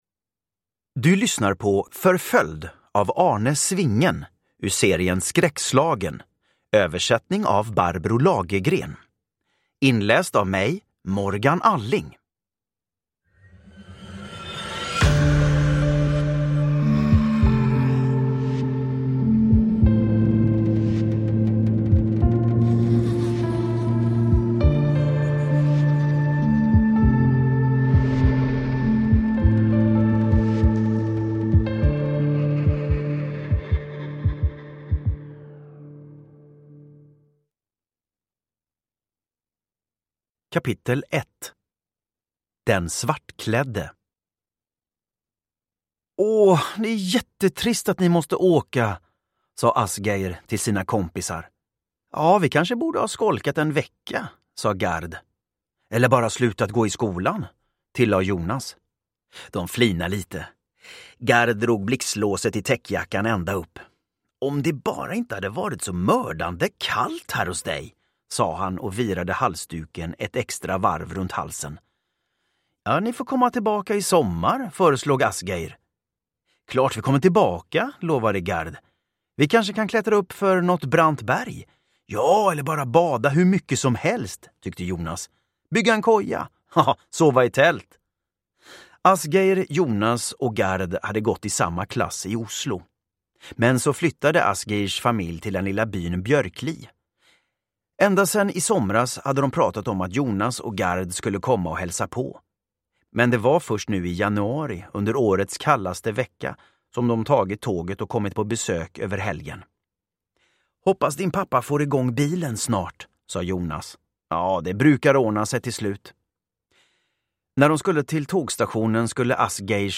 Förföljd – Ljudbok
Uppläsare: Morgan Alling